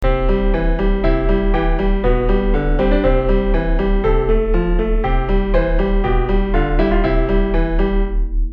80cent 低い。
今日の症状に合わせてメニエール体験音源を作った（暇人かよ）。
（実際は元音源がもっとセンター寄りから聴こえるんだけど、そうするとデチューンぽい音色になってしまうので完全LRで表現。）